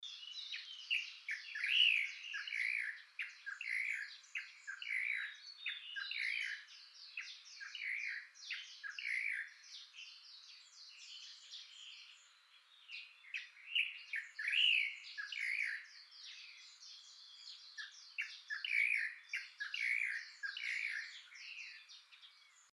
シロガシラ
【分類】 スズメ目 ヒヨドリ科 シロガシラ属 シロガシラ 【分布】本州(迷鳥：千葉、石川、広間、山口)、九州(冬鳥：長崎)、沖縄(留鳥) 【生息環境】林縁、農耕地、草原に生息 【全長】18.5cm 【主な食べ物】昆虫、果実 【鳴き声】地鳴き 【聞きなし】「チョットコイ、チョットコイ」「キョッキョッビーア」